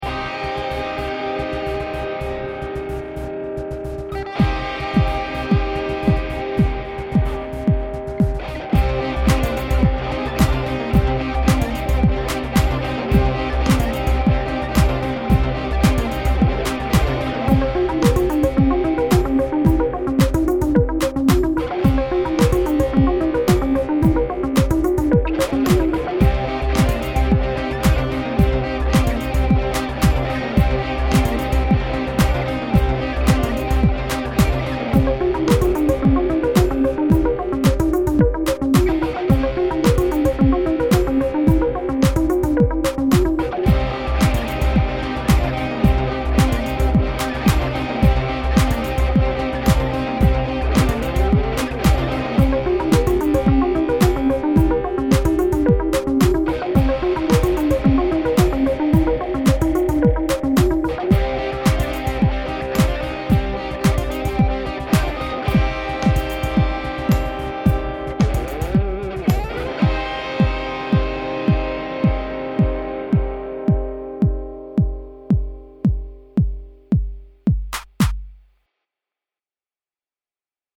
This is the original waveform. Although at a loud dynamic level, notice the peaks and valleys in the waveform.
Studio Recording - plain.mp3